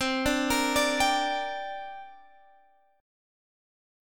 C7sus2 chord